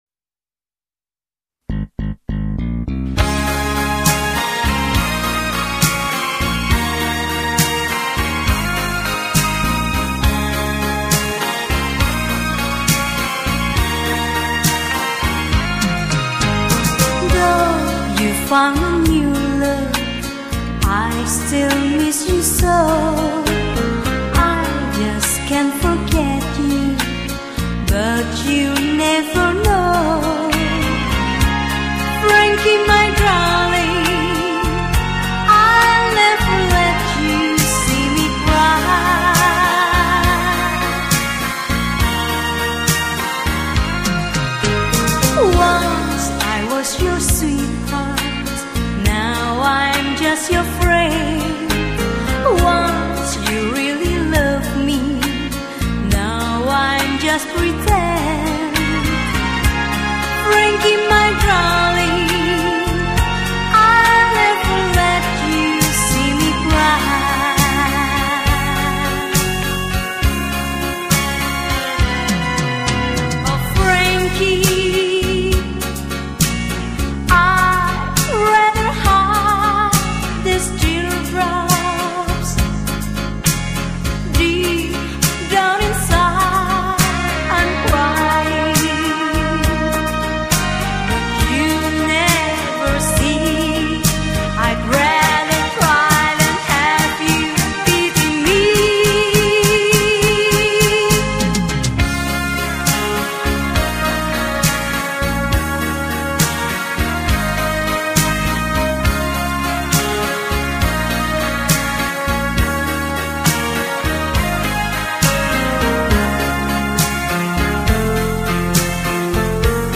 美国乡村音乐(永恒的经典)五张专辑地址索引